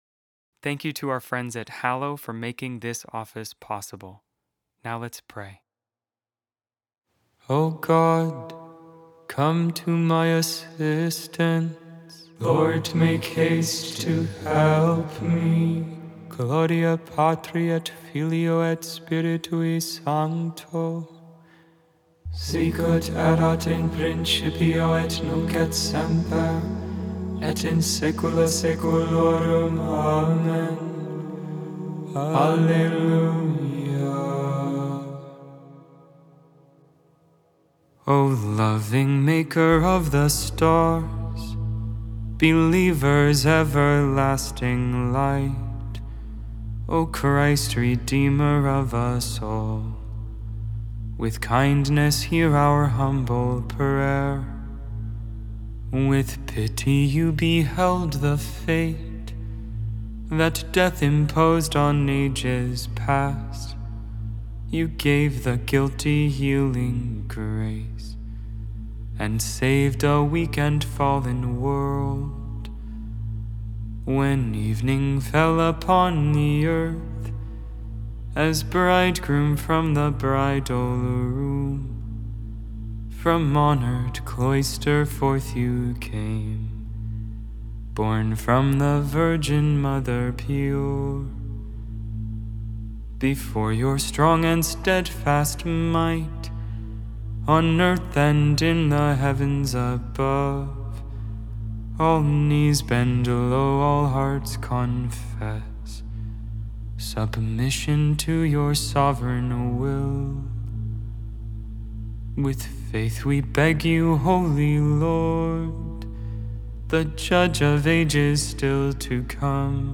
Vespers, Evening Prayer for the 1st Wednesday in Advent, December 3rd, 2025.Memorial of St. Francis Xavier, Priest Made without AI. 100% human vocals, 100% real prayer.